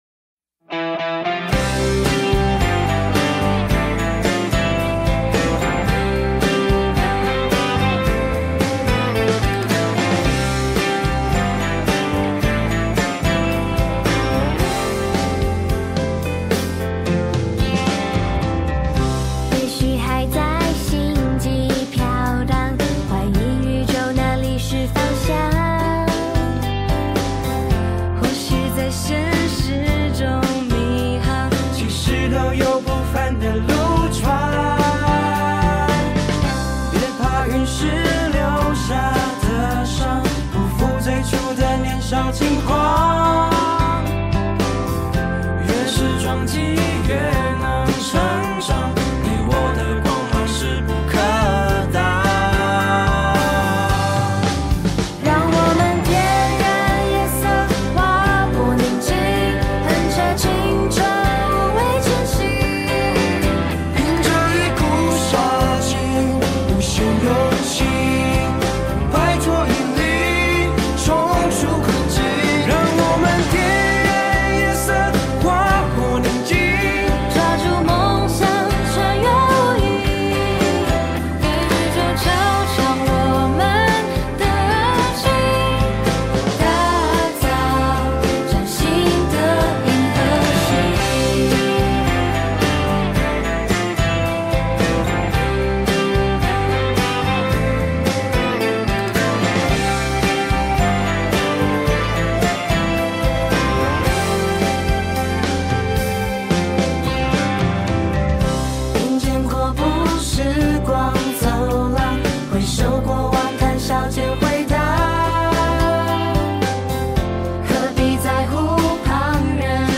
很青春洋溢的一首歌~